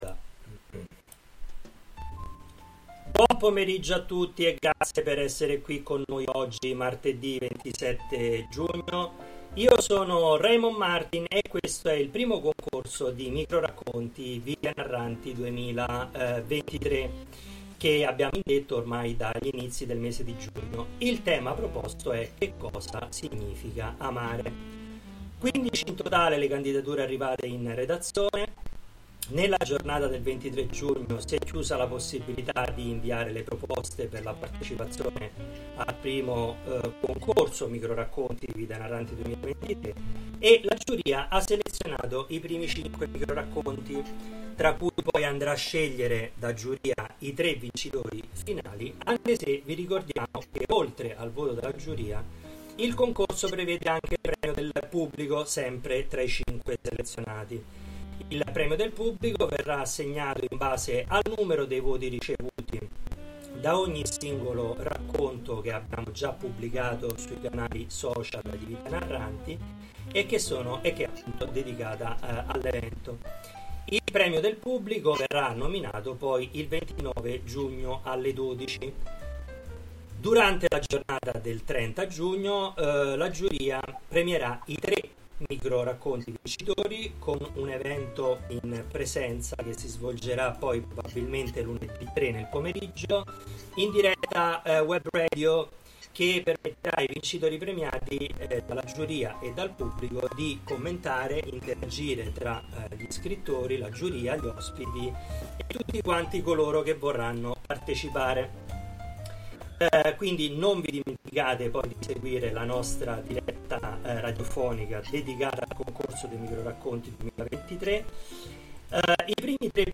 Letture-MR.mp3